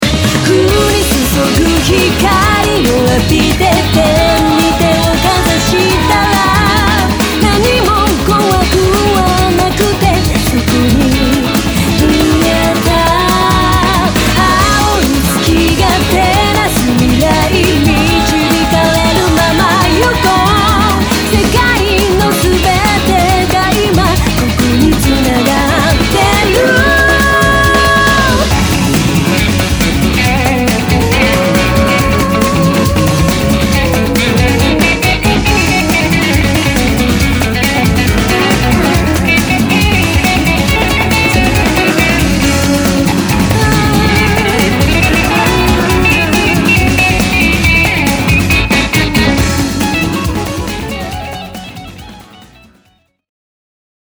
Vocal and chorus :